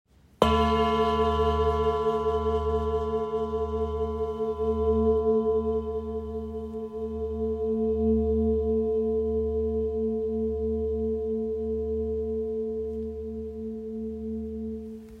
GSB Singing Bowl 23.5cm - 29.5cm
Light in weight yet remarkably strong in sound, this bowl produces deep, grounding vibrations that can be both felt and heard.